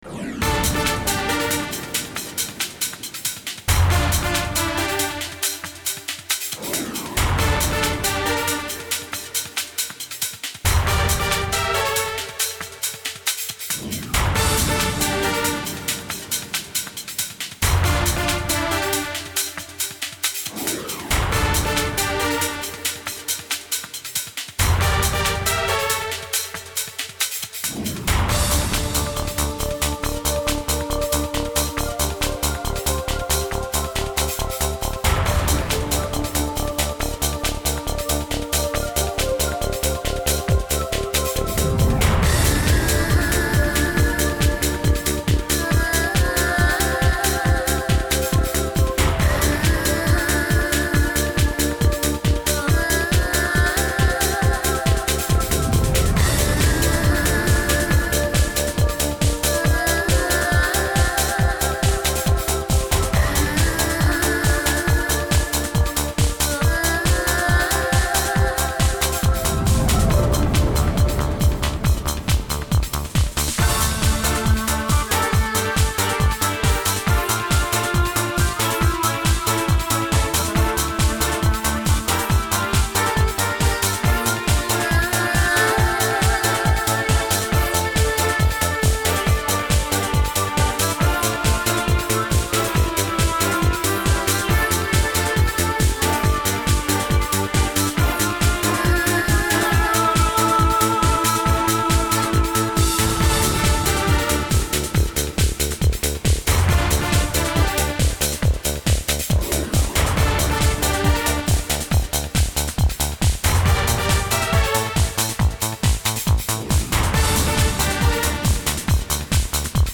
ambient to light techno sound